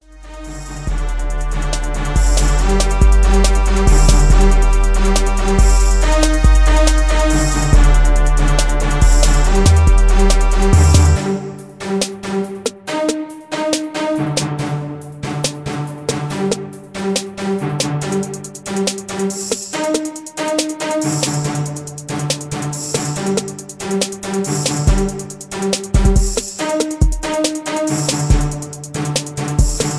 rap beat